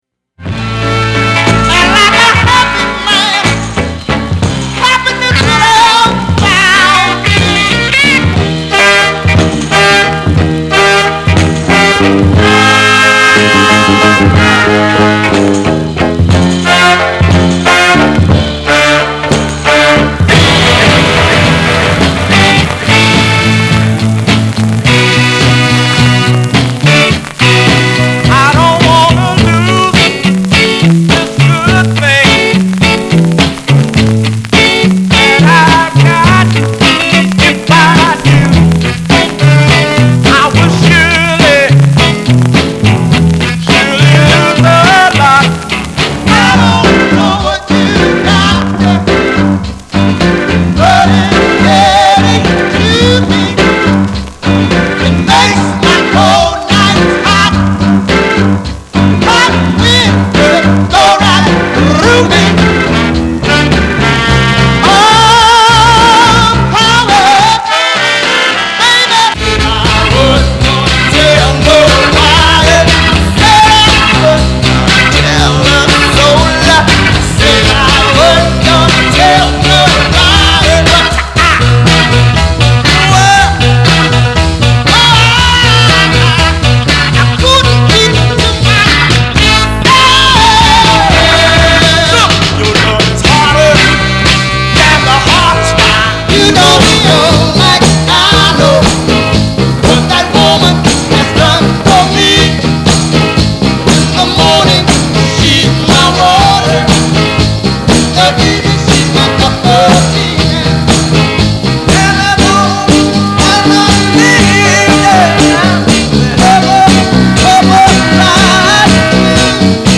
R&B、ソウル
/盤質/両面やや傷あり/US PRESS